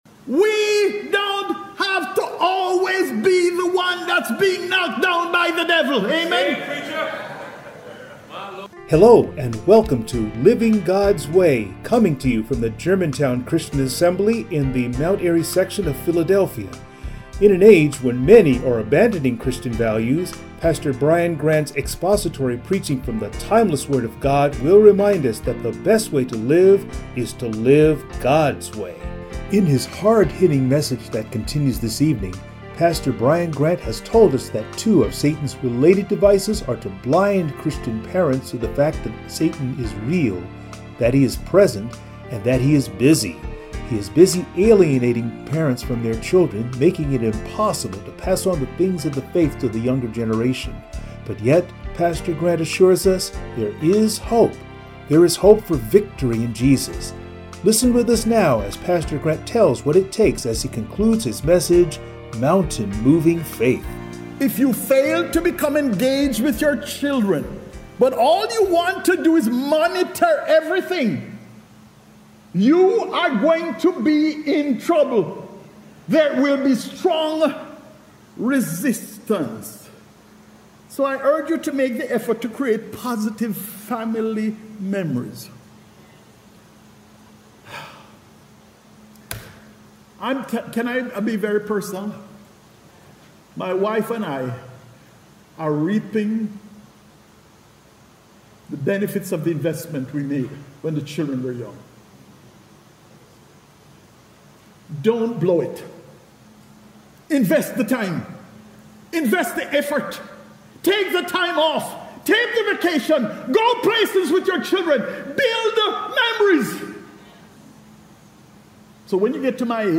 Passage: Matthew 17:1-21 Service Type: Sunday Morning